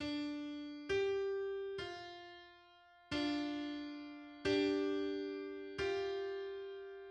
Melodic and harmonic intervals.
Melodic_and_harmonic_intervals.mid.mp3